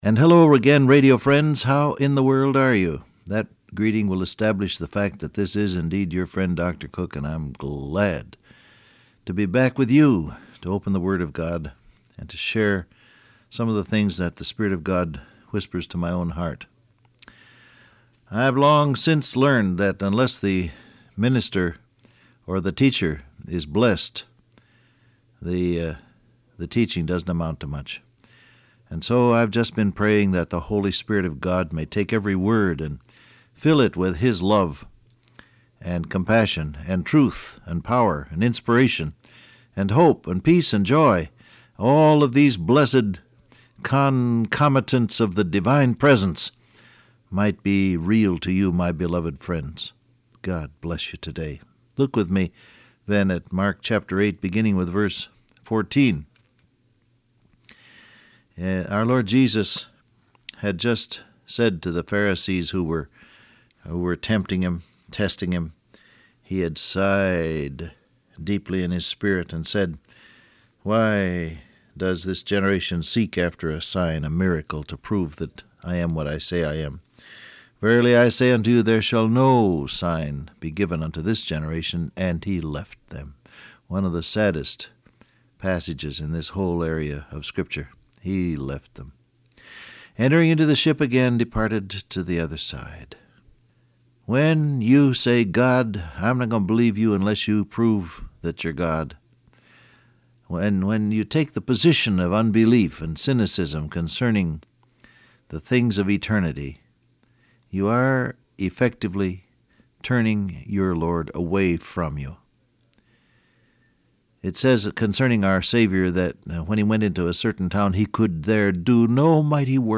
Download Audio Print Broadcast #6438 Scripture: Mark 8:14 , Luke 12 Topics: Hypocrite , poitics , be authentic Transcript Facebook Twitter WhatsApp And hello again radio friends.